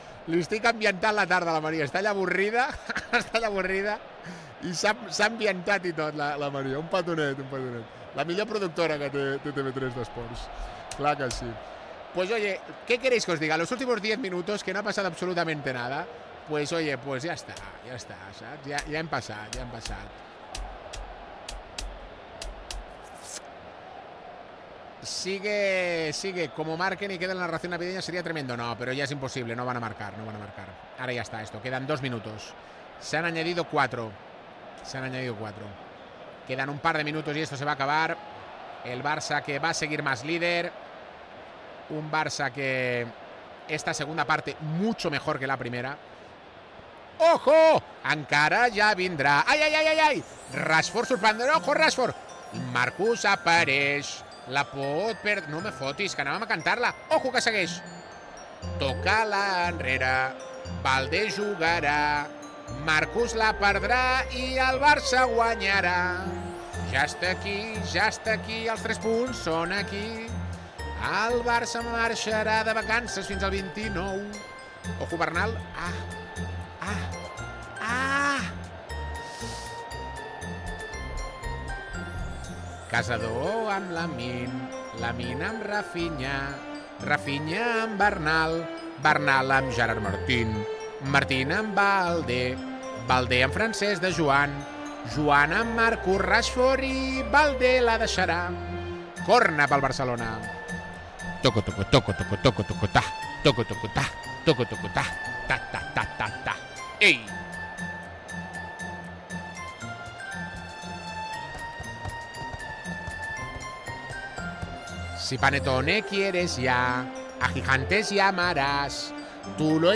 Transmissió dels minuts finals del partit de la Lliga masculina de primera divisió entre el Vila Real i el Futbol Club Barcelona.
Esportiu